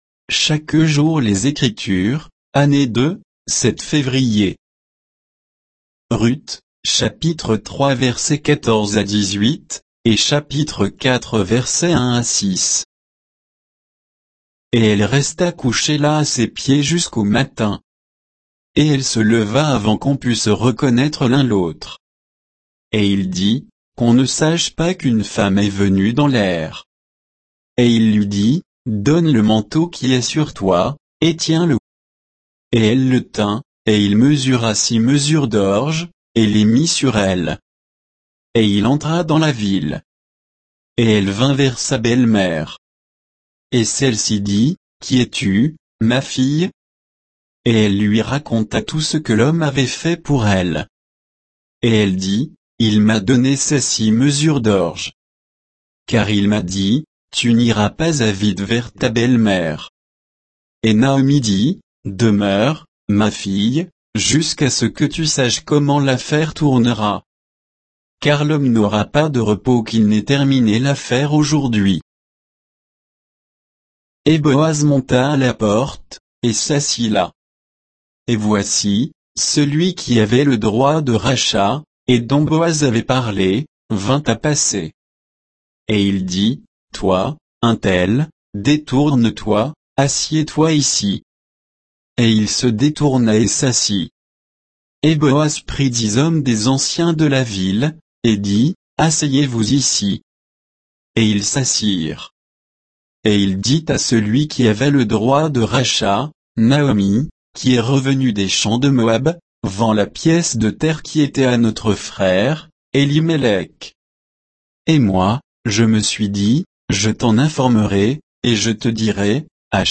Méditation quoditienne de Chaque jour les Écritures sur Ruth 3